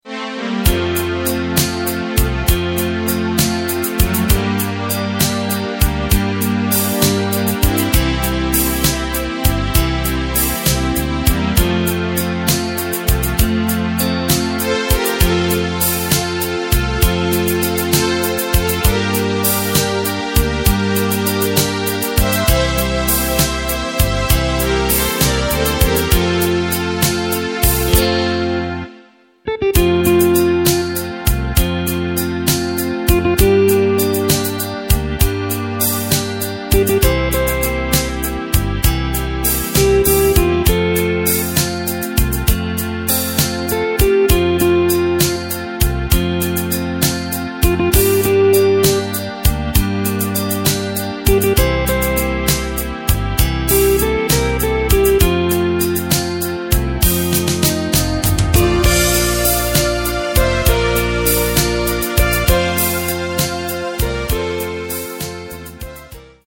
Takt:          6/8
Tempo:         99.00
Tonart:            F
Schlager aus dem Jahr 1982!